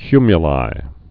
(kymyə-lī)